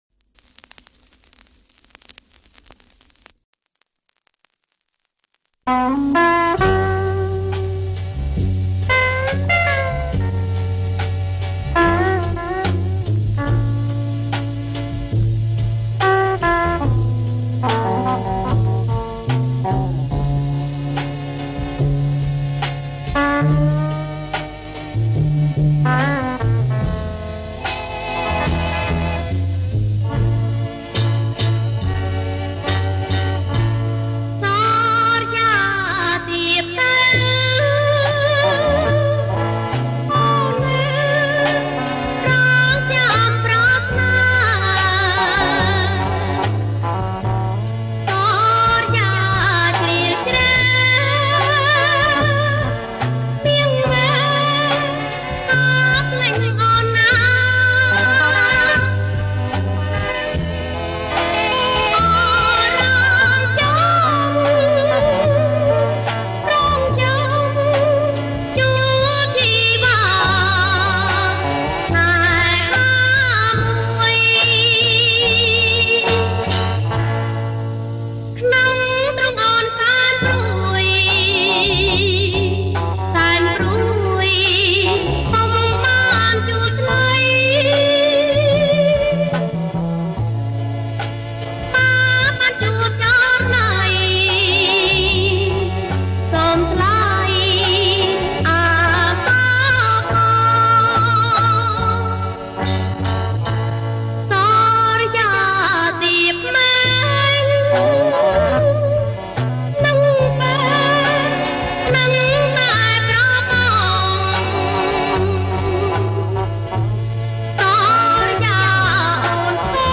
• ប្រគំជាចង្វាក់ Bolero Jerk